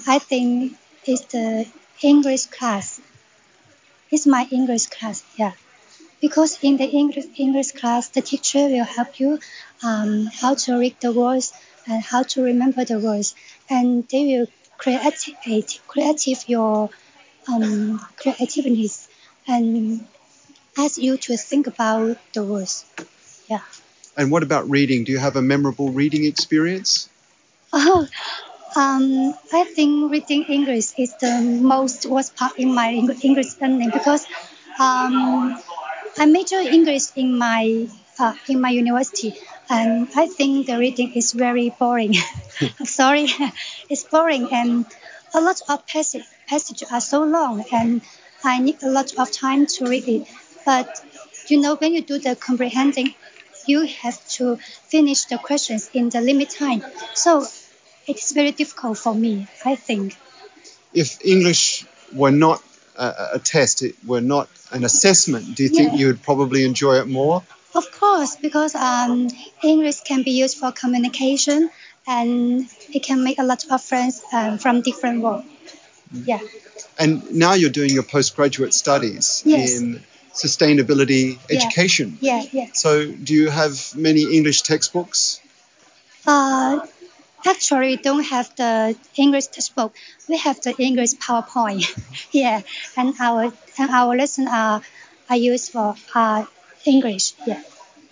A postgraduate student with a bachelor’s degree in English found reading to be boring but feels knowing the language can broaden her opportunities.